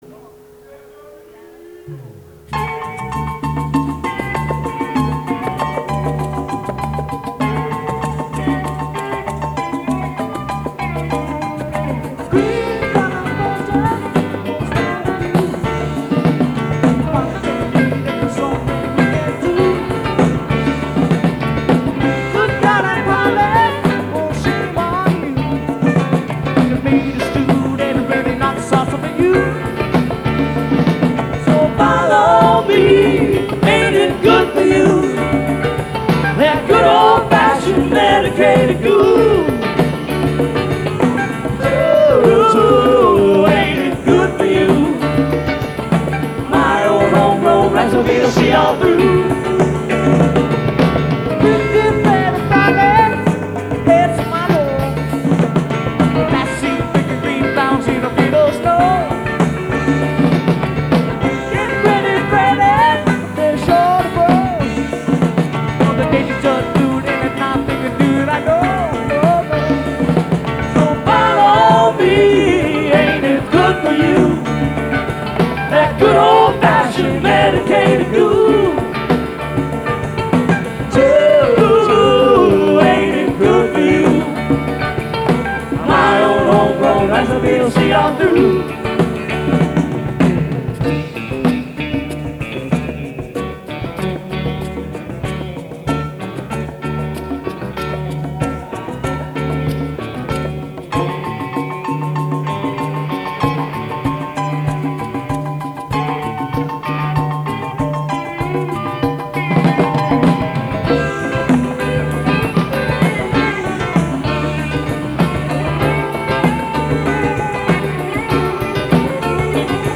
Live Recordings
Norwalk, CT